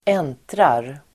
Uttal: [²'en:trar]